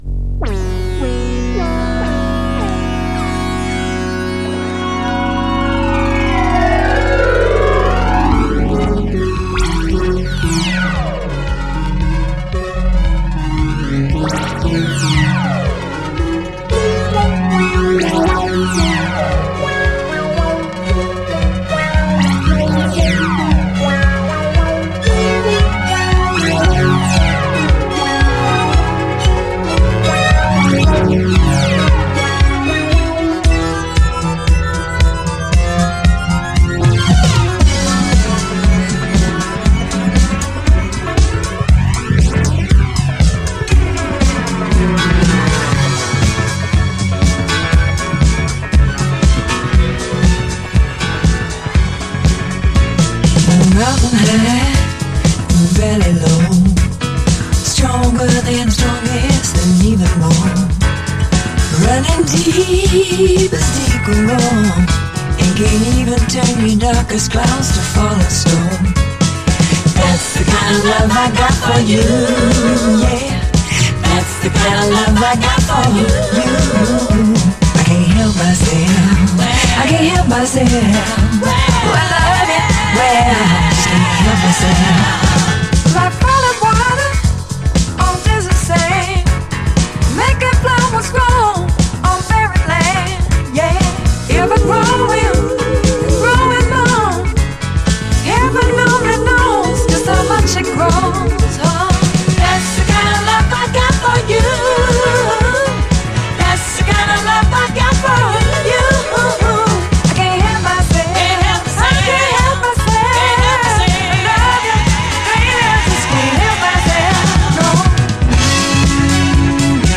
SOUL, 70's～ SOUL, DISCO
演奏はLAのフュージョン系名プレイヤー達。